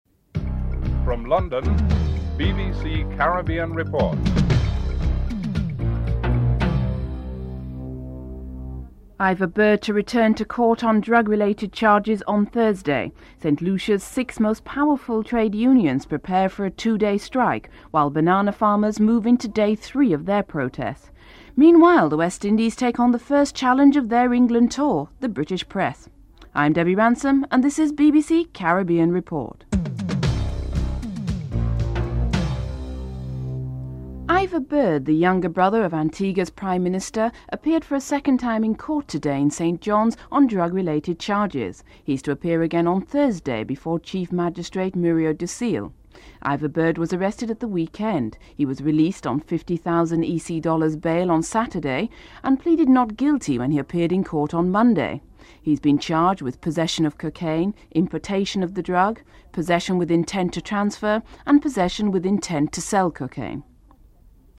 8. Recap of top stories (15:02-15:24)